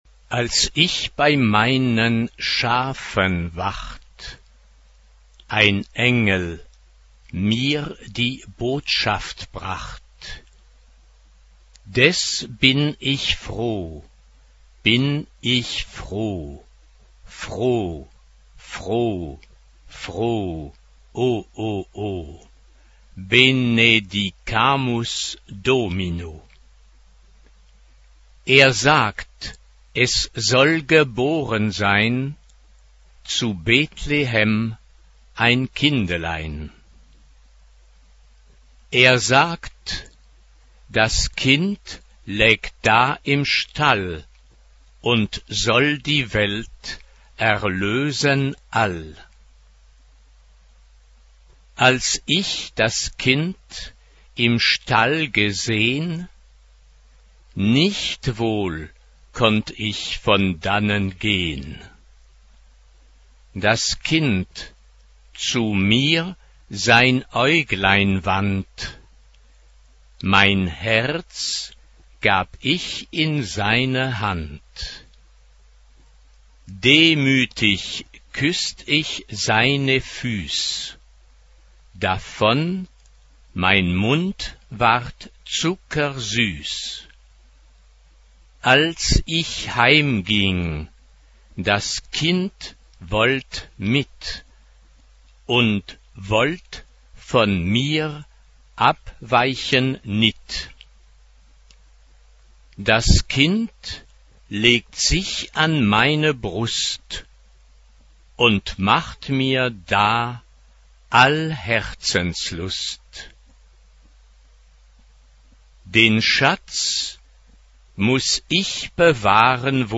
Genre-Style-Forme : Sacré ; Profane ; noël ; Traditionnel Type de choeur : SA (2 voix égales de femmes OU égale(s) d'enfants ) Instrumentation : Clavier (ad lib)
Tonalité : sol mineur